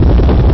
fl_fly.ogg